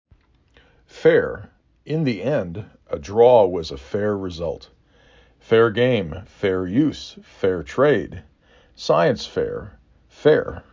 4 Letters, 1 Syllable
3 Phonemes
f e r